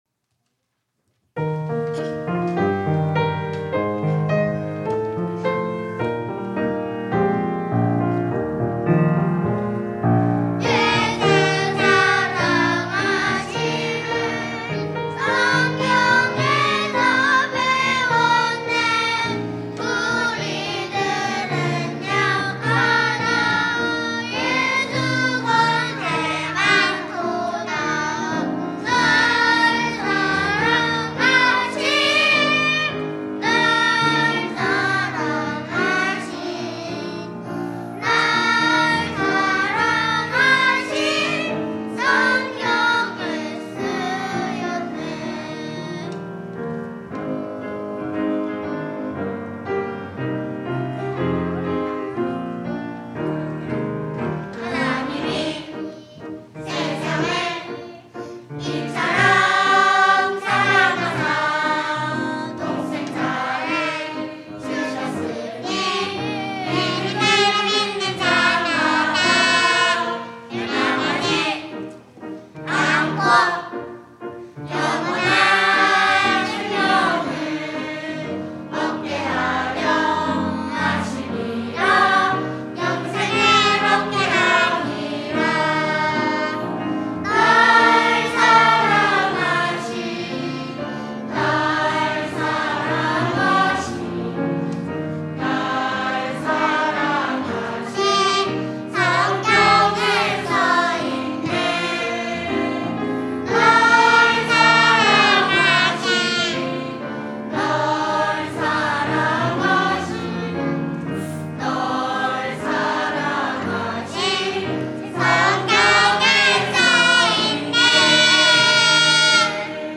온세대예배 찬양대